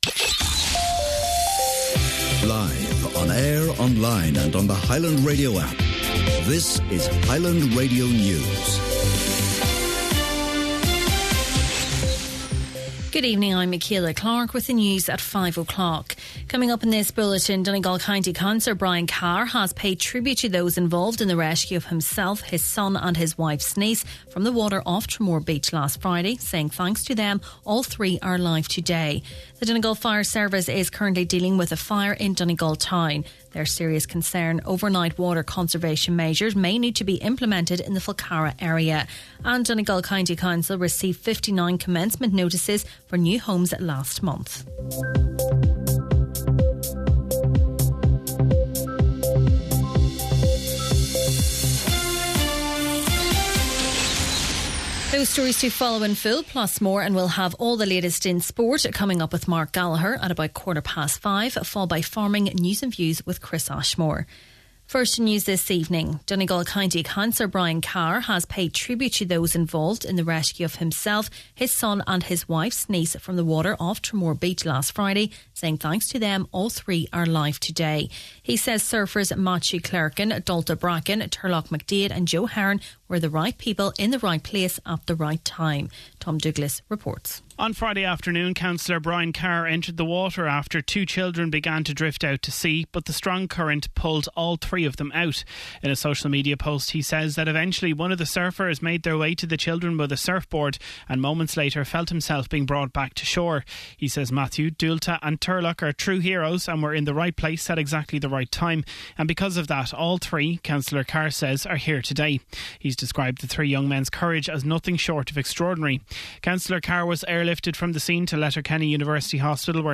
Main Evening News, Sport, Farming News and Obituaries – Thursday, August 21st